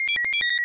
描述：DTMF音调拼写了openscad的数量，压缩。比OpenSCAD DTMF更圆。
Tag: DTMF OpenSCAD